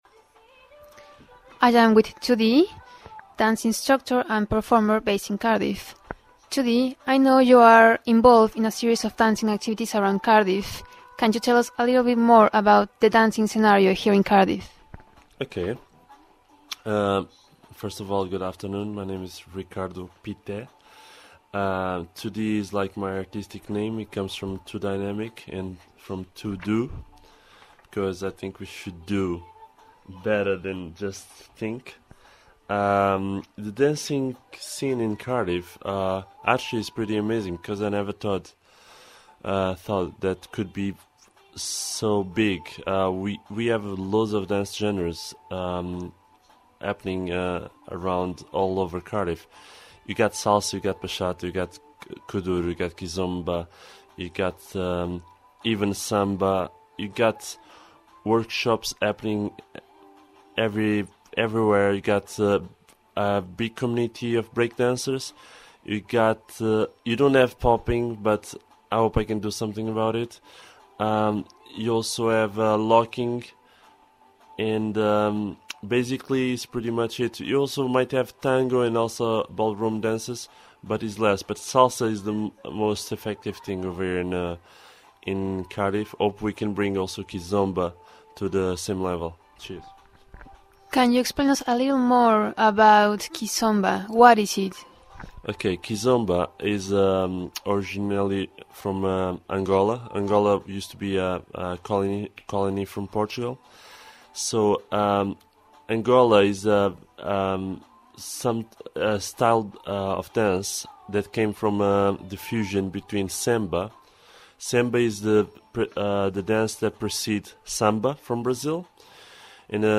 2D- kizomba interview